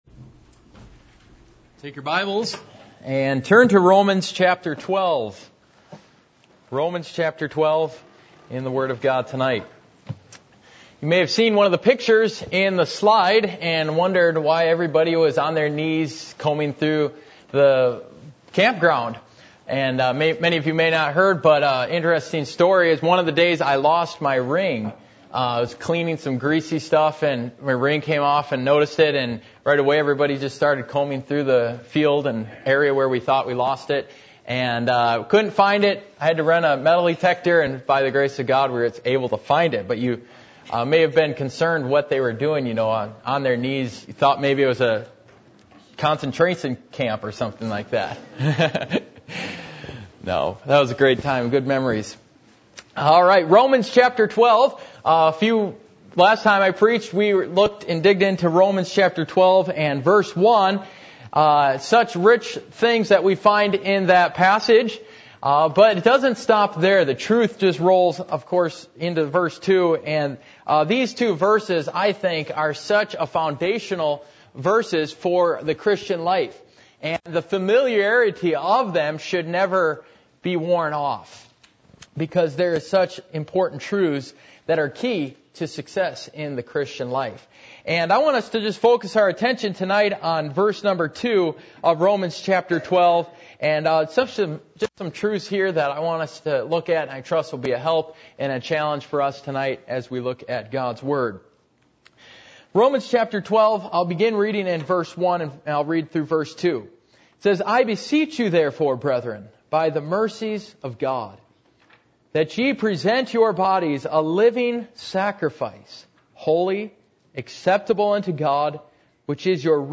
Passage: Romans 12:1-2, Romans 8:28, Romans 1:28 Service Type: Sunday Evening %todo_render% « An Overcomer or a Succumer?